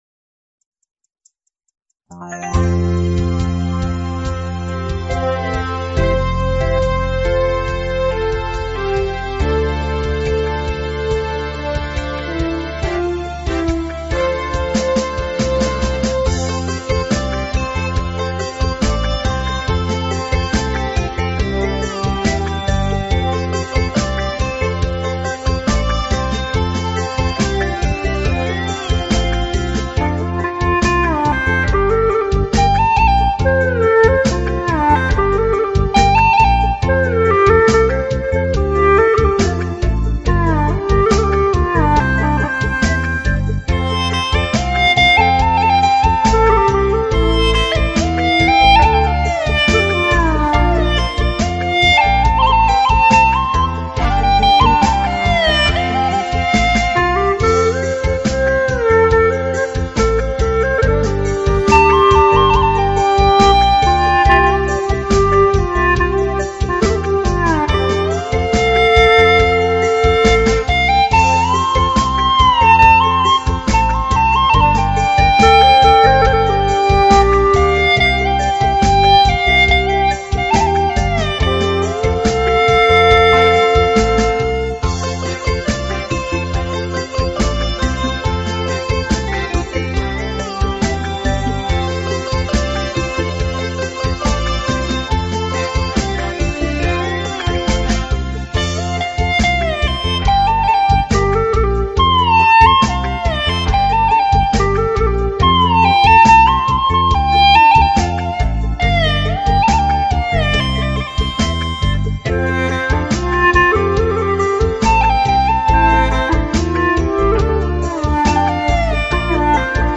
调式 : F 曲类 : 民族